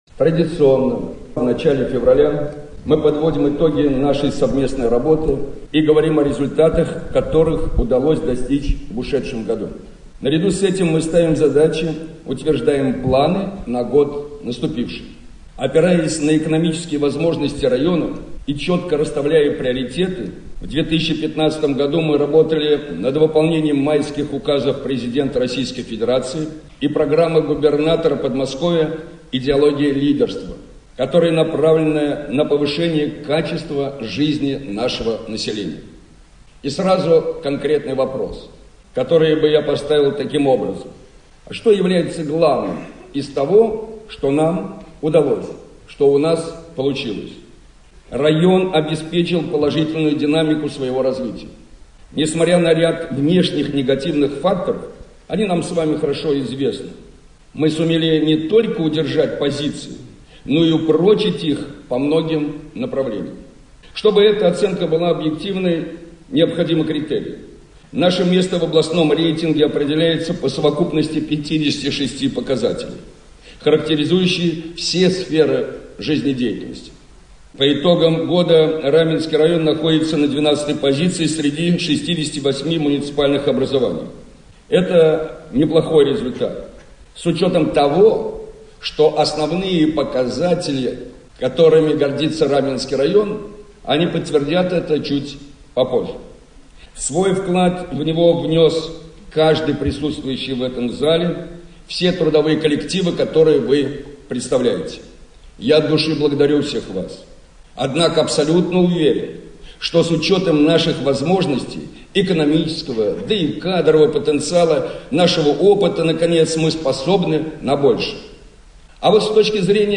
09.02.2016г. в эфире Раменского радио